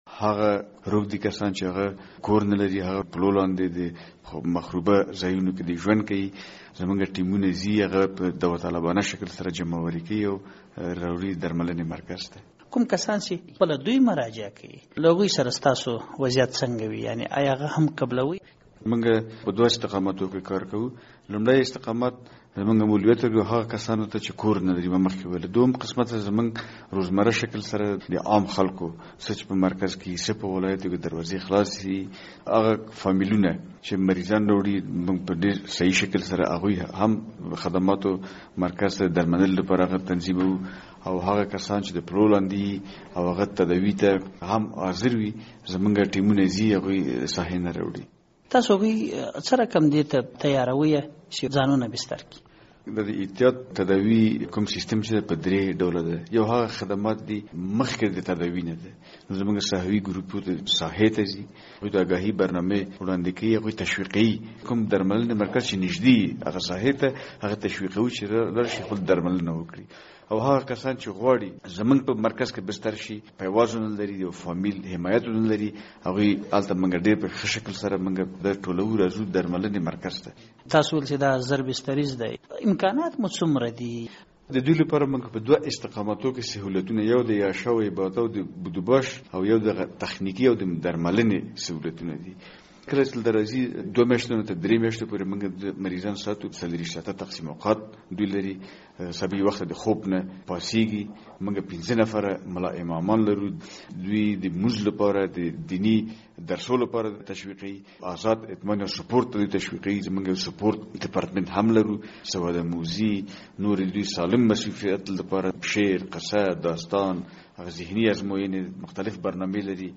د زهرو کاروان پروګرام مرکه